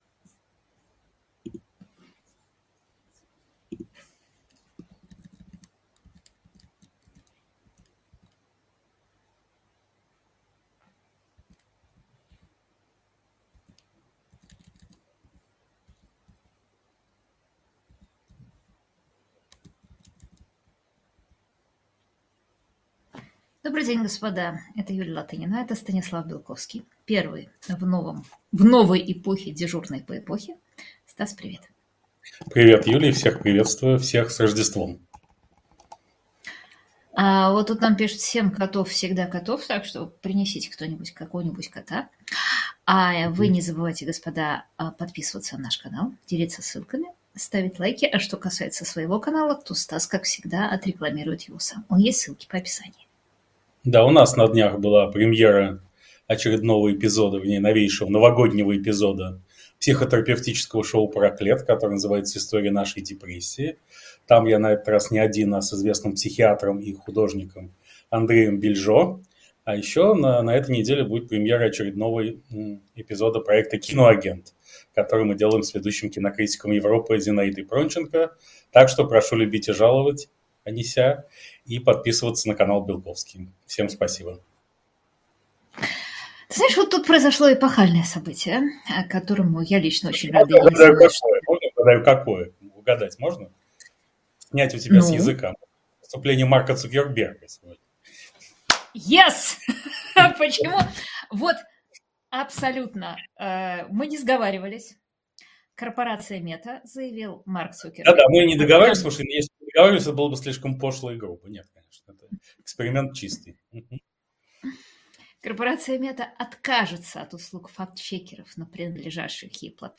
1 Как в 2024 году одна политическая эпоха сменила другую. Интервью "Репаблик" 1:21:57